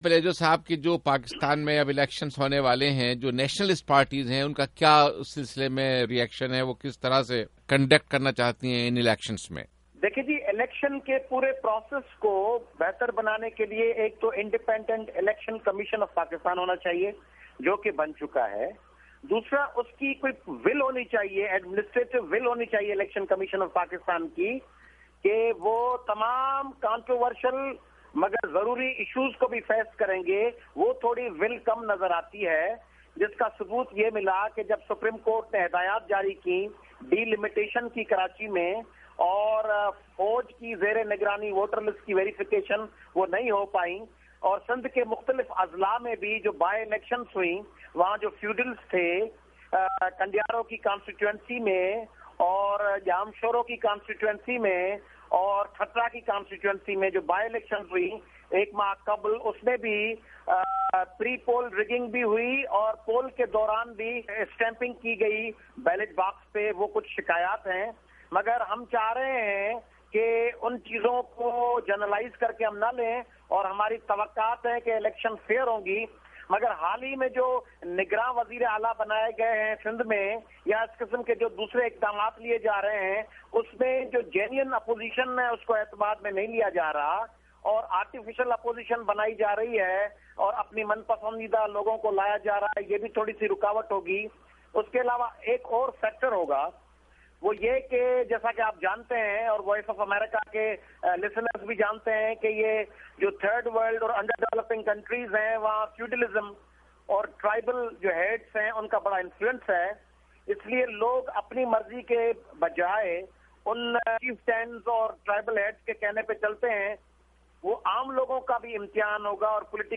’وائس آف امریکہ‘ کی اردو سروس سے ایک انٹرویو میں اُنھو ں نے کہا کہ گرچہ انتخابات میں مجموعی طور پر ووٹنگ برادری یا قبیلوں کی بنیاد پر ہی ہوگی، جو ہماری روایات رہی ہیں، تاہم، عمومی طور پر اِس میں بہت سے عوامل شامل ہوں گے جن میں لسانیت اور خیبر پختونخواہ، بلوچستان اور پنجاب کے بعض حصوں میں مذہبی وابستگی شامل ہے۔